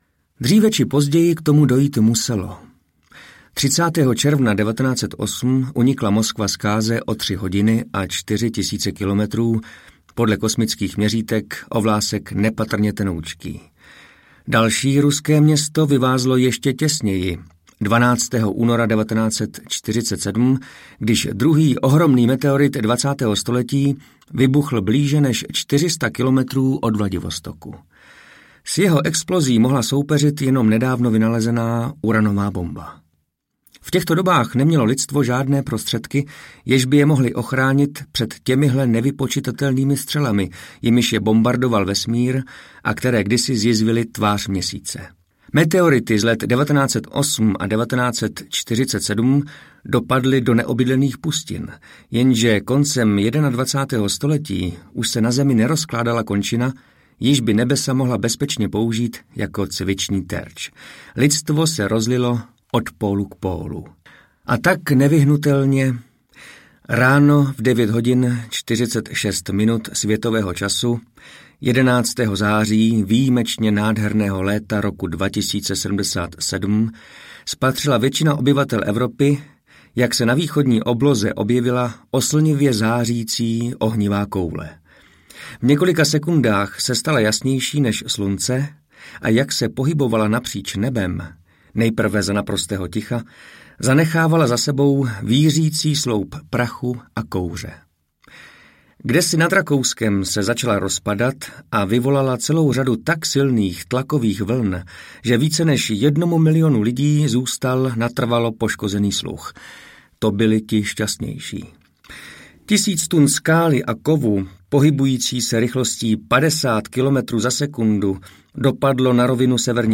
Setkání s Rámou audiokniha
Ukázka z knihy